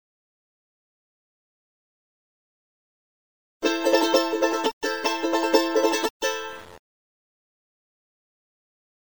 ポトシ地方のチャランゴ奏法 〜 右手の弾き方 〜
（ハ）□　↓↑↓↑　↓↑↓×　↓　□